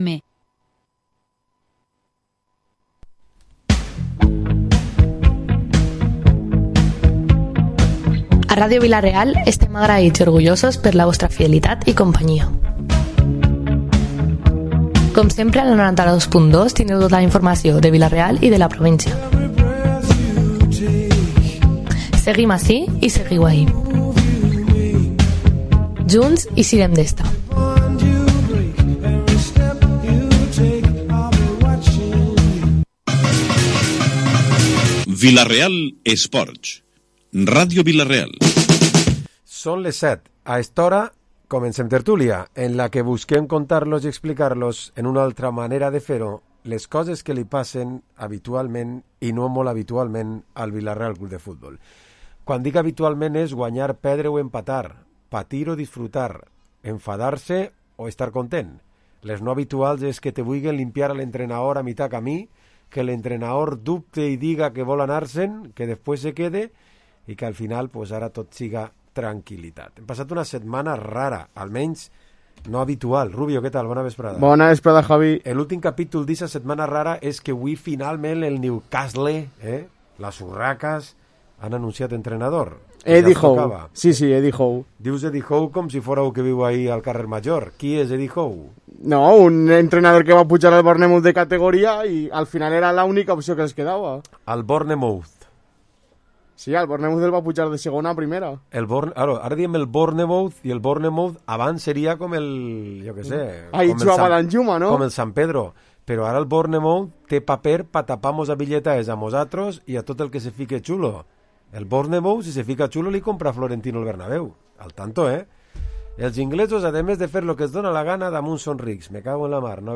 Programa esports tertúlia dilluns 8 de novembre